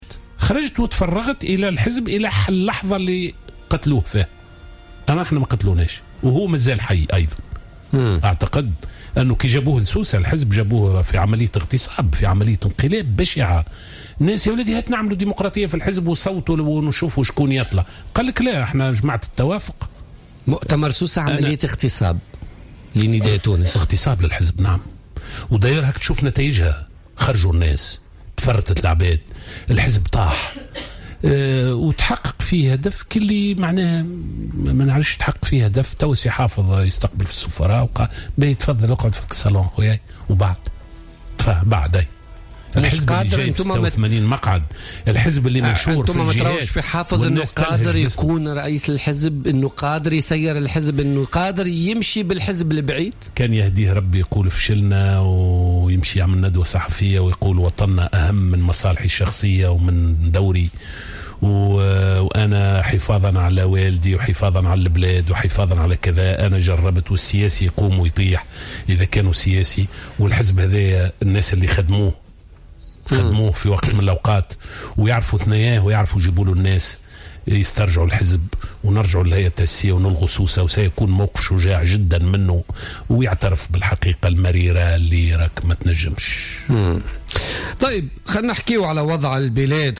قال القيادي السابق في نداء تونس لزهر العكرمي ضيف برنامج بوليتكا لليوم الأربعاء 16 مارس 2016 إن ما حدث في سوسة "انقلاب" وعملية "اغتصاب بشعة" لحزب نداء تونس.